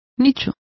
Complete with pronunciation of the translation of recesses.